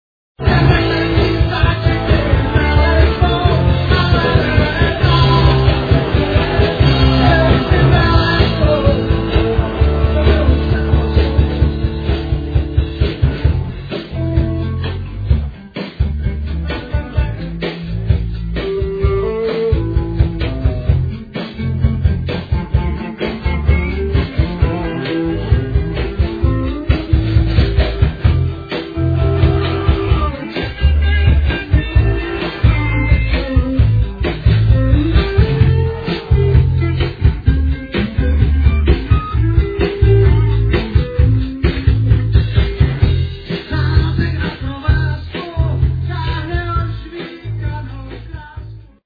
el. guitar
Live from Prague [2002].